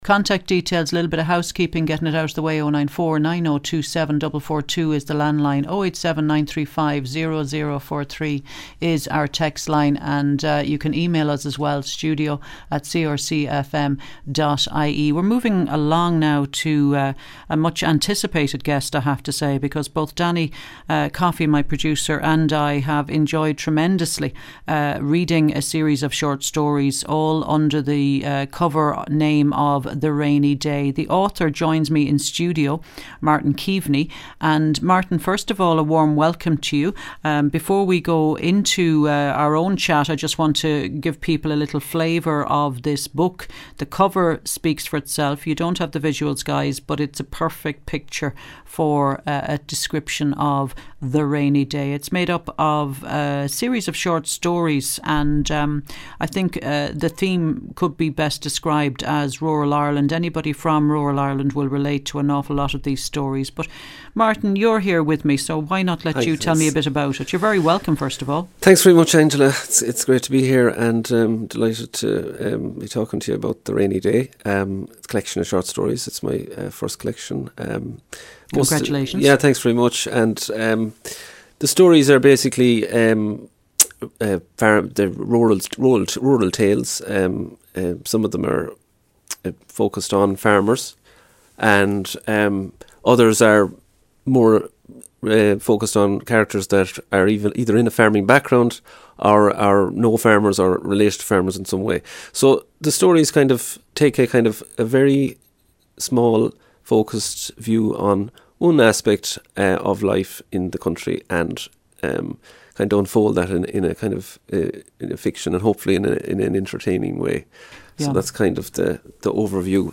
Radio Interview – The Rainy Day